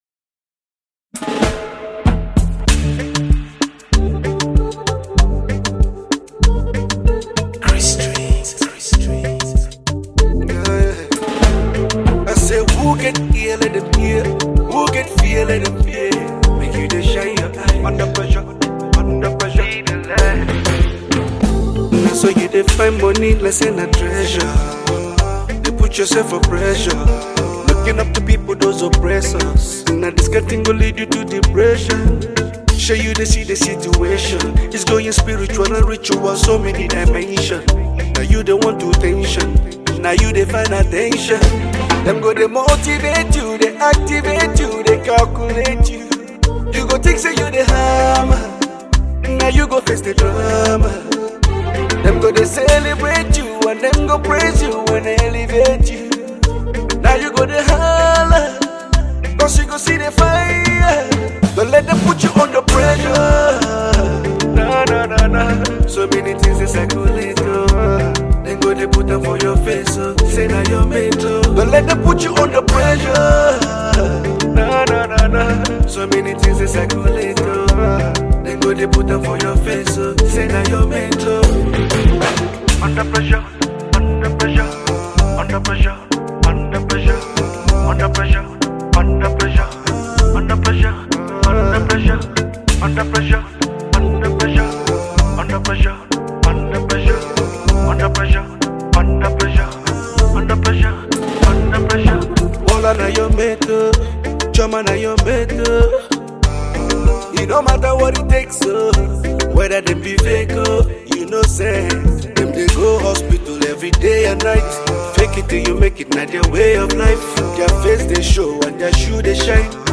Nigerian Afrobeats
blends Afrobeat rhythms with contemporary sounds